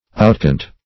Outcant \Out*cant"\